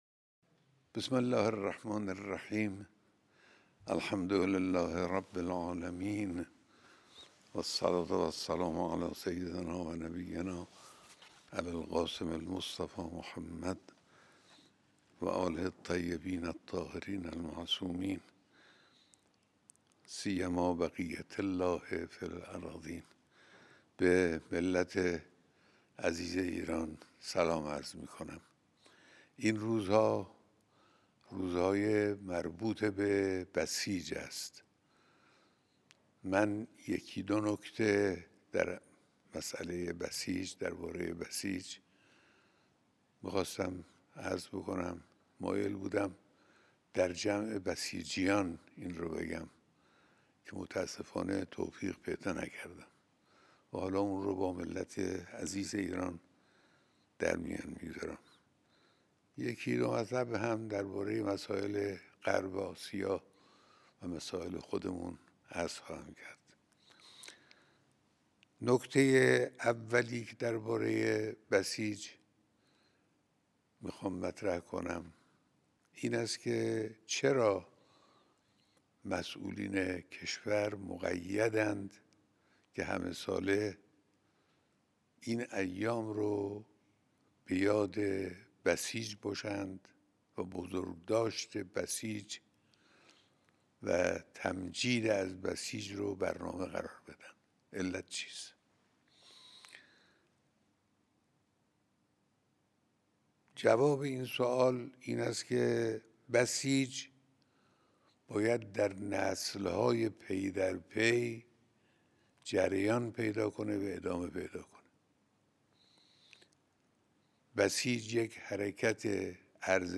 سخنرانی تلویزیونی خطاب به ملت ایران
سخنان تلویزیونی رهبر انقلاب خطاب به ملت ایران